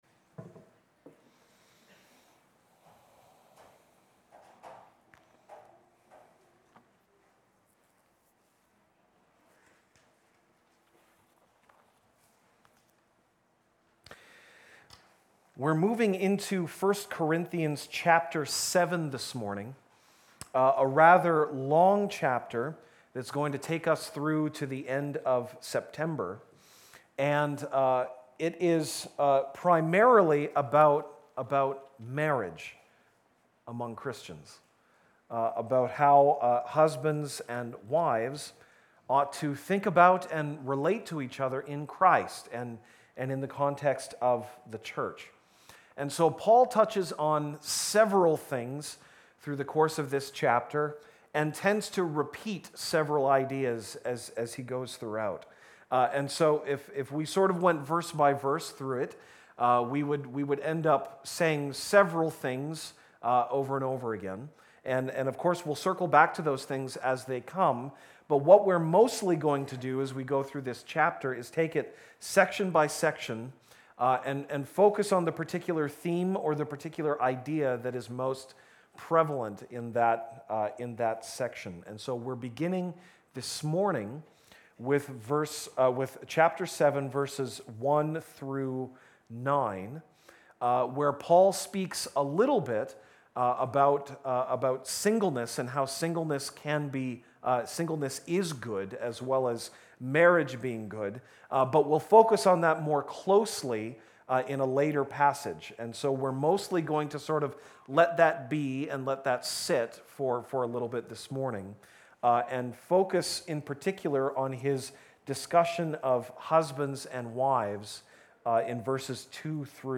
August 19, 2018 (Sunday Morning)